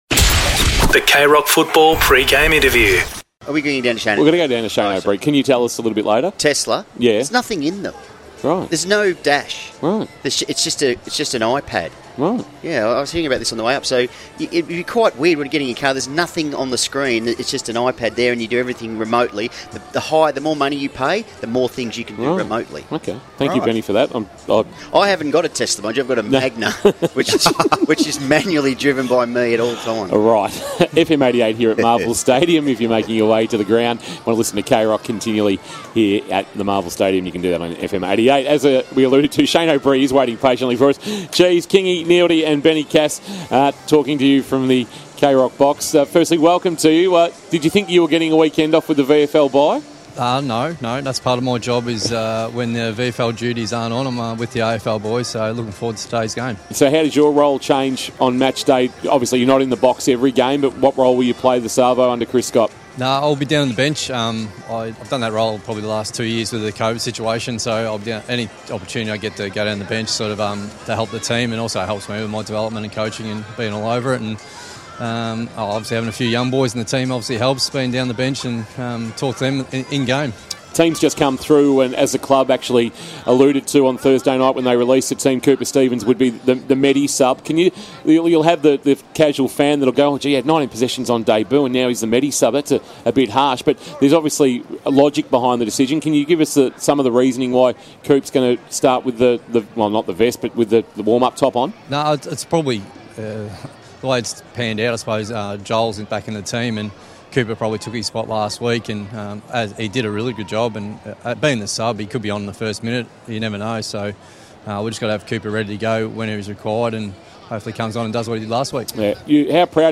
2022 - AFL ROUND 9 - ST KILDA vs. GEELONG: Pre-match Interview